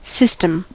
system: Wikimedia Commons US English Pronunciations
En-us-system.WAV